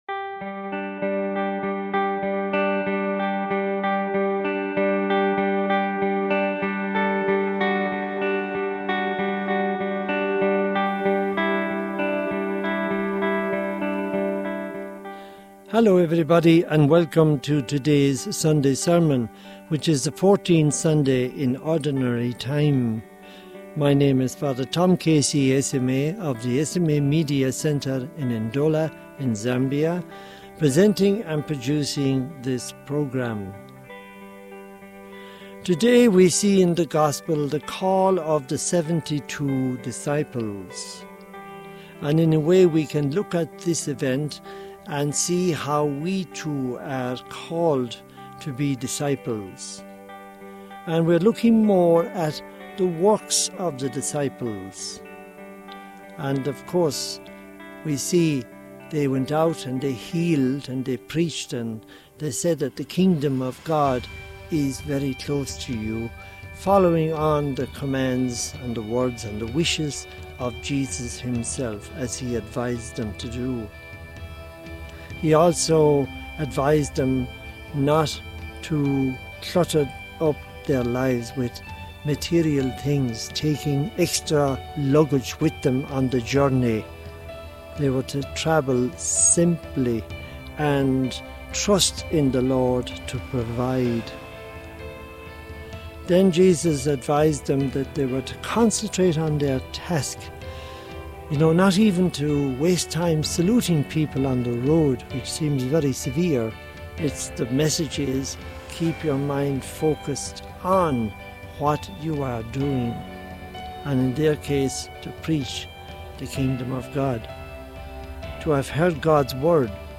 Homily for the 14th Sunday Year C | Society of African Missions